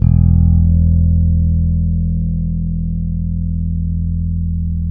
-MM JAZZ E 2.wav